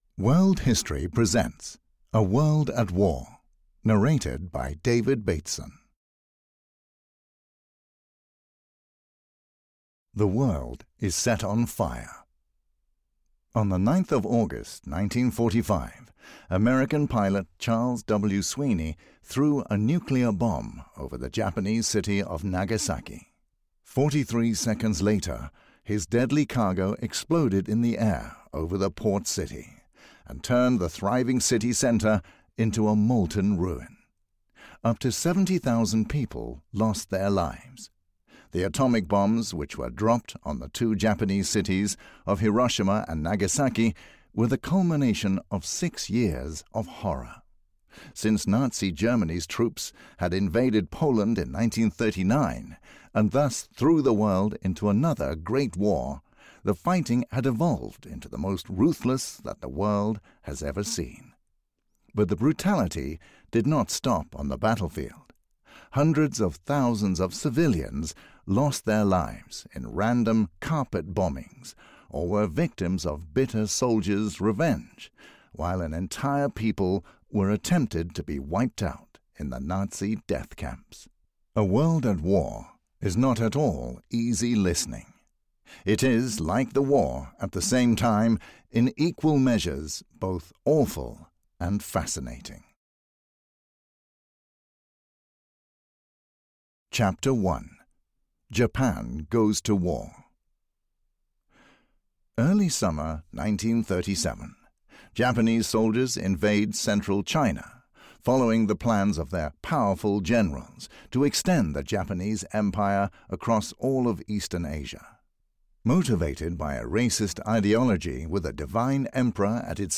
A World at War (EN) audiokniha
Ukázka z knihy